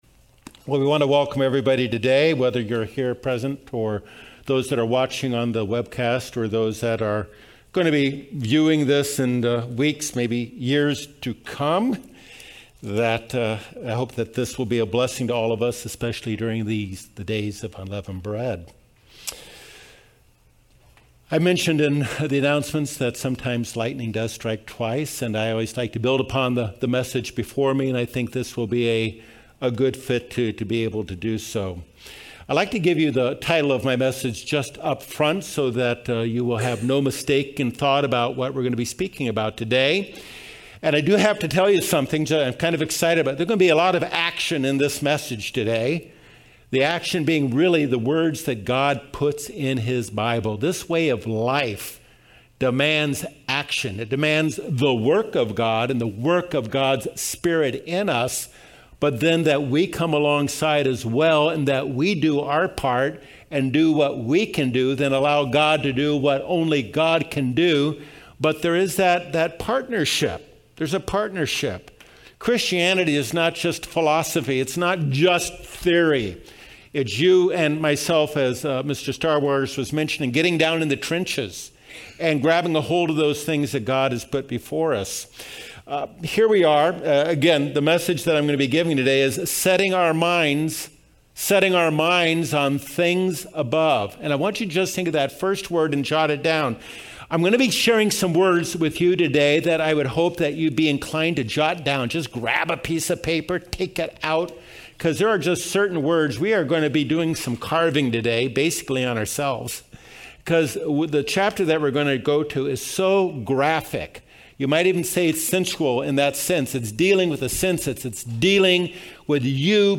This message given on the last day of the Festival of Unleavened Bread outlines and defines step by step the God-given spiritual GPS given to us in Colossians 3, as we continue our pilgrimage to the ultimate Promised Land. Christianity is not simply philosophy--at its core it concerns dynamic intervention by our Heavenly Father and Christ and dynamic life changing response on our part.